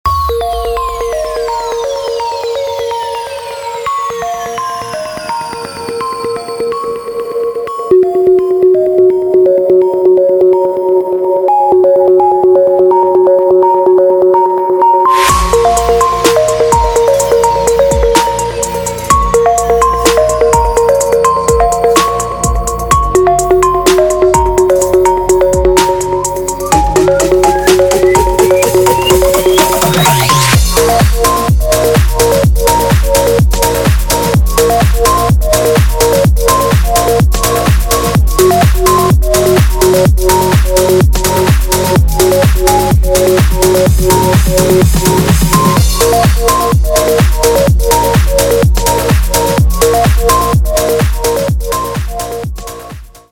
• Качество: 192, Stereo
громкие
мелодичные
без слов
club
progressive trance
Trance
Euro Techno